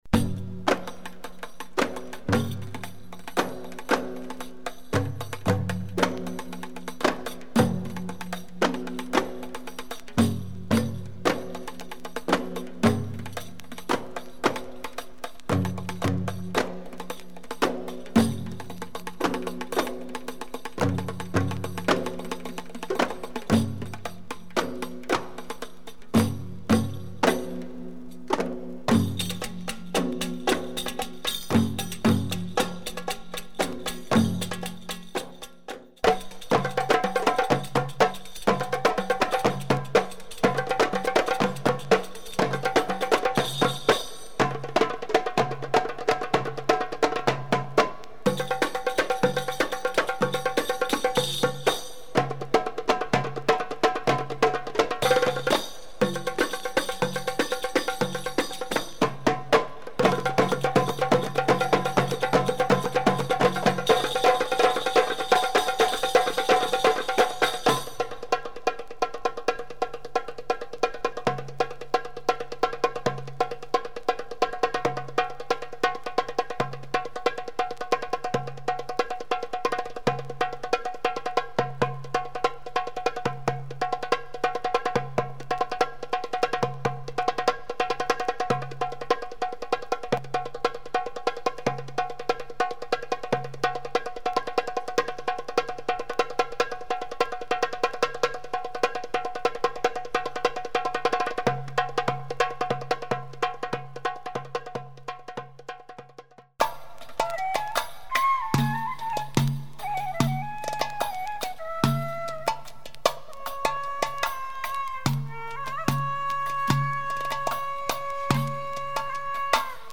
Recorded in San Francisco in the late 70s...